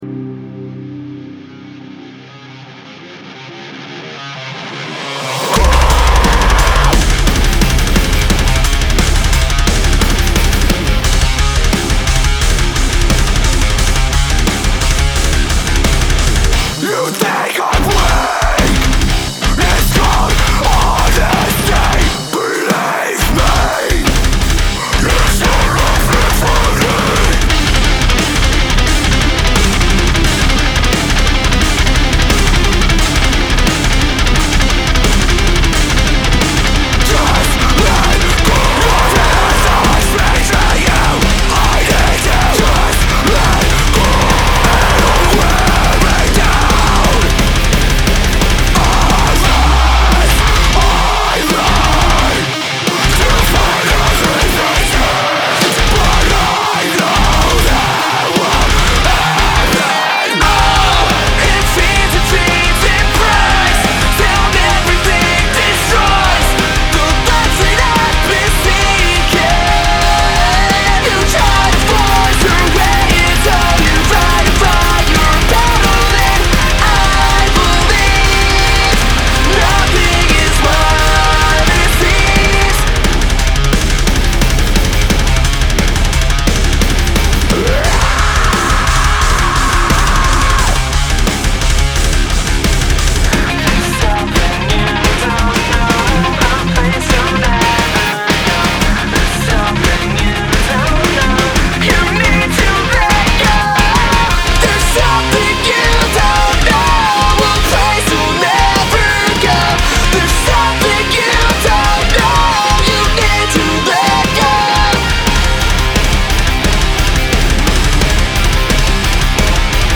fight music